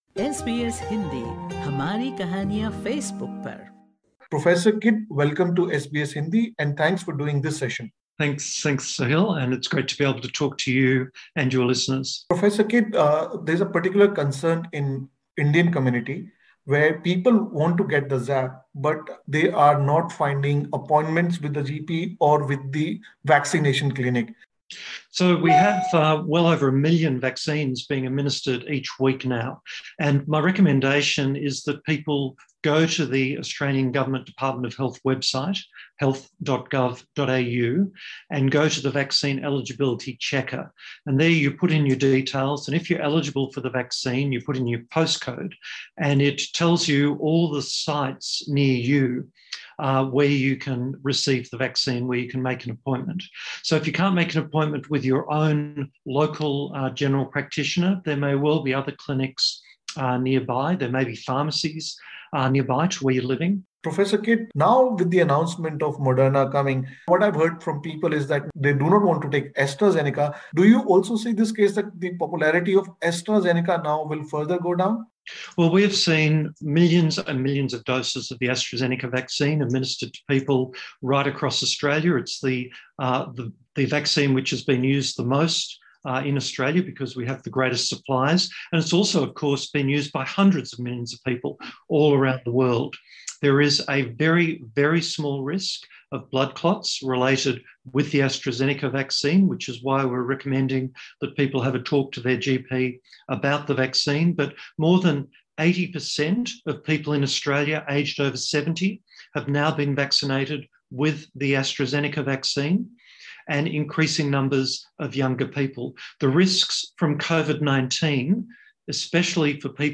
In an interview with SBS Hindi, Prof Kidd urged people to adhere to public health restrictions.